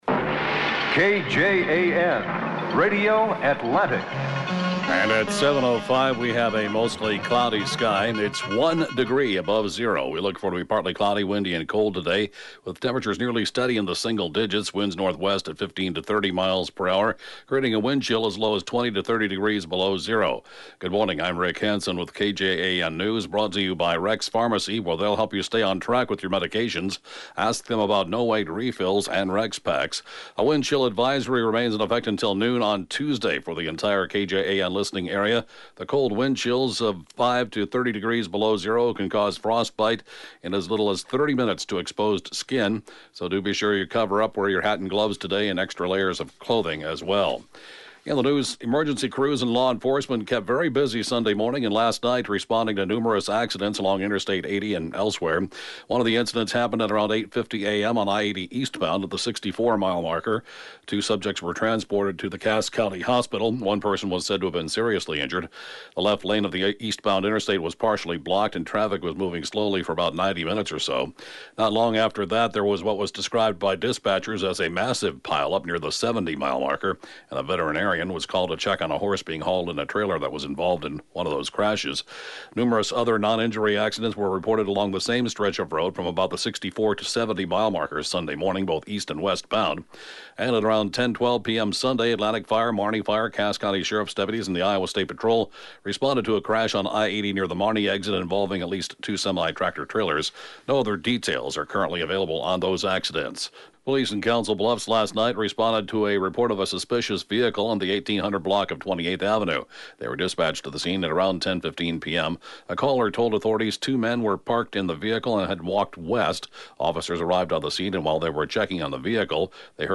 (Podcast) KJAN Morning News & funeral report, 1/15/2018